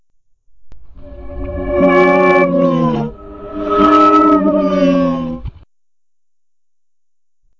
TEC 闹鬼的声音 " 过路鬼1
描述：噪音怪异的奇怪的惊吓鬼的恐怖可怕的令人毛骨悚然的幽灵幽灵阴险 你听到的声音可能比你想象的更接近......
Tag: 怪异 闹鬼 诡异 令人毛骨悚然 邪恶 恐怖 怪异 恐惧 噪音 吓人 幽灵